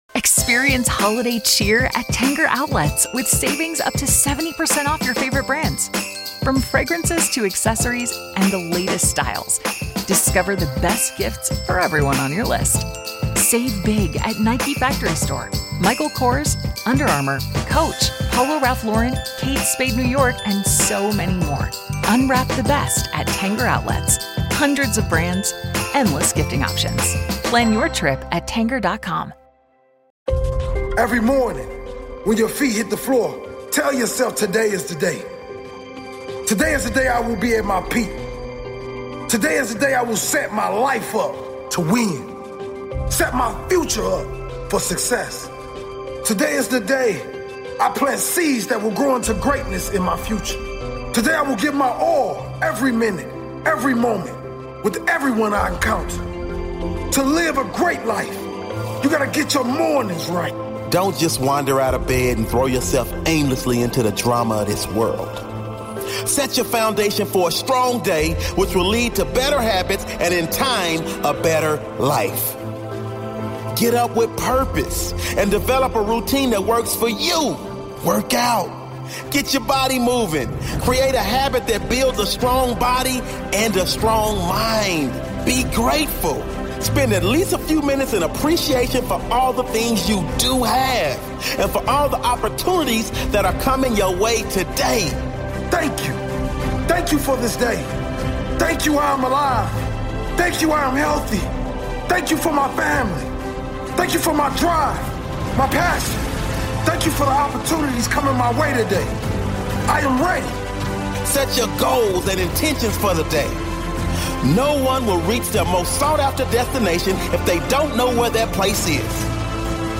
DAVID AND GOLIATH - The Most Powerful Motivational Speech